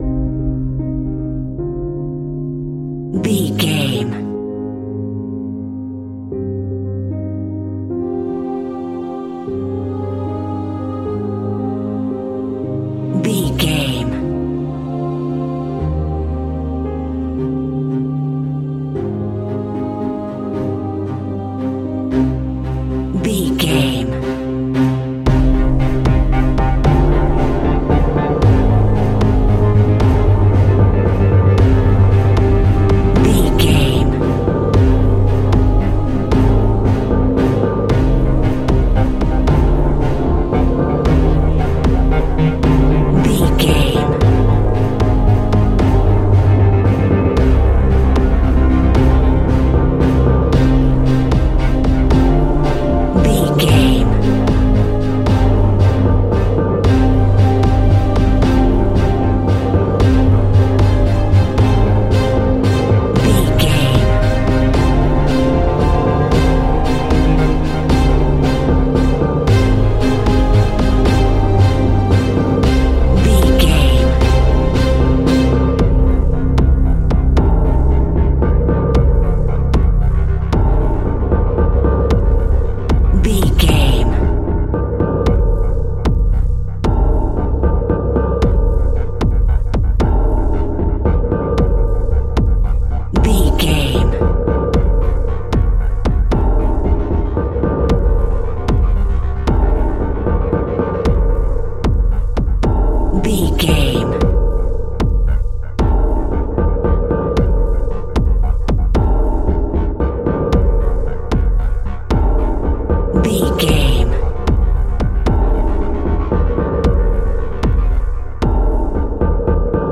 Aeolian/Minor
strings
percussion
synthesiser
brass
violin
cello
double bass